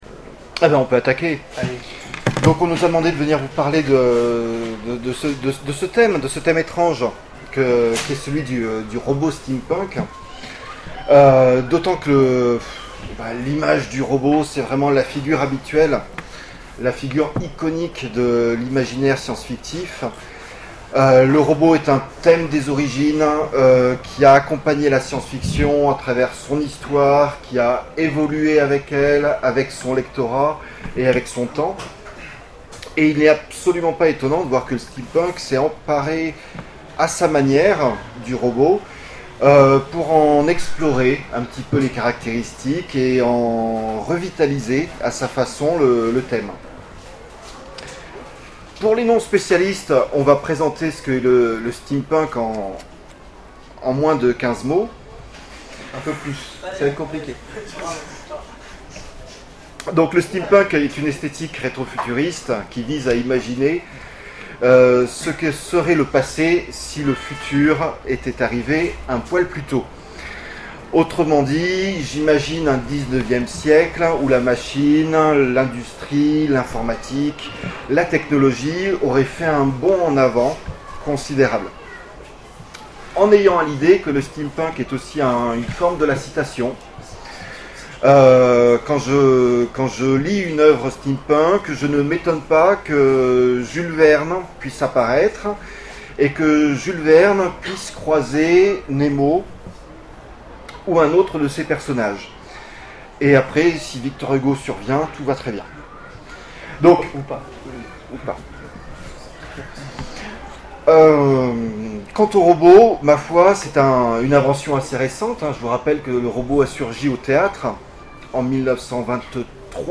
Les Futuriales 2013 : Conférence Retro-Robot : Tour d’horizon du robot streampunk